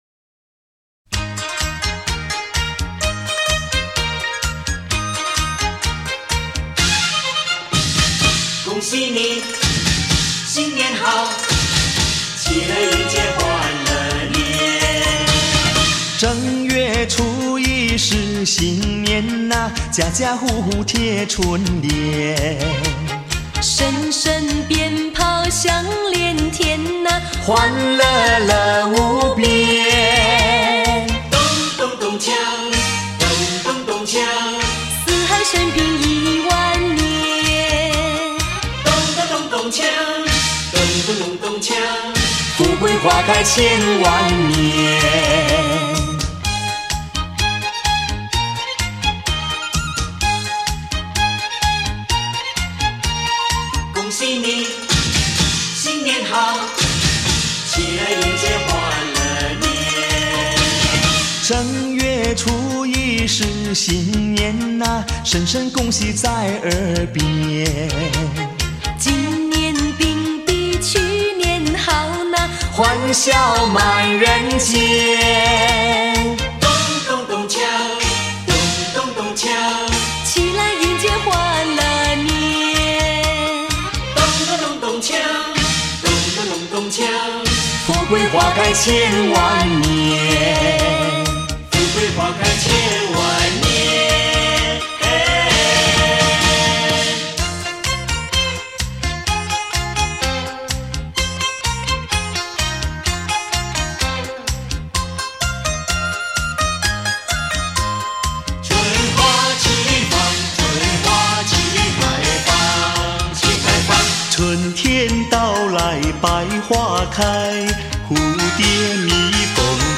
华语、福建、广东、客家、英语大拼盘